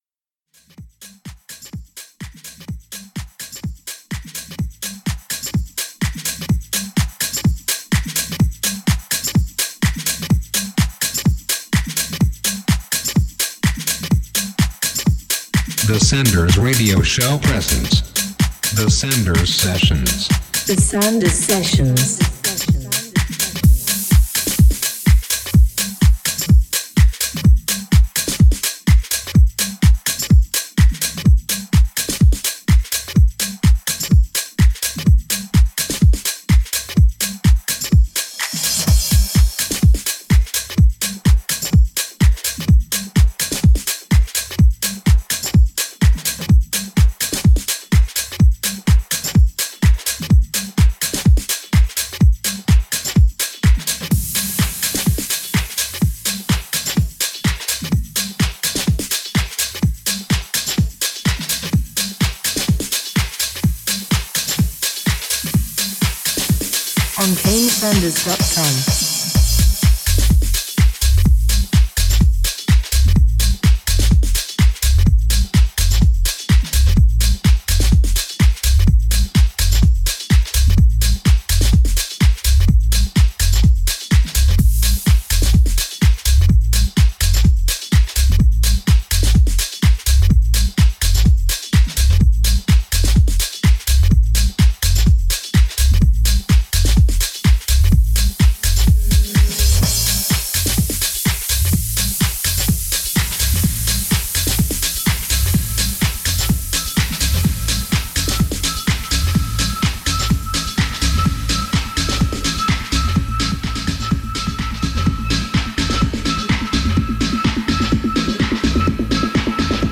one hour of good sounds mixed